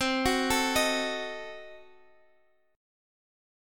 Ebdim/C Chord